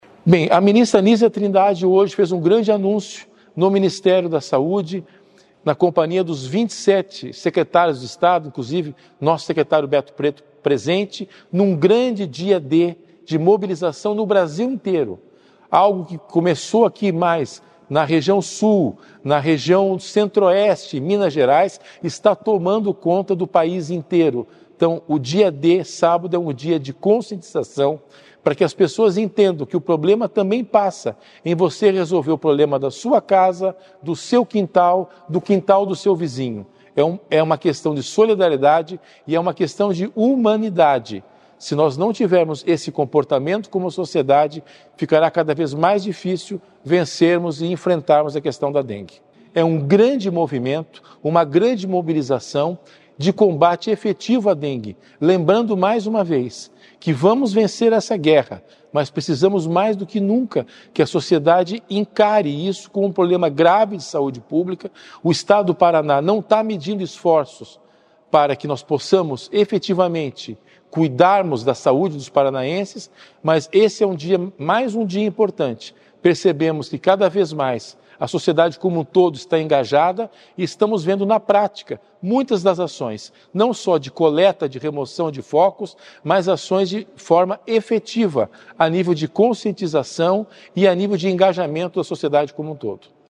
Sonora do diretor-geral da Sesa, César Neves, sobre o Dia D de combate a dengue que acontece nacionalmente no próximo sábado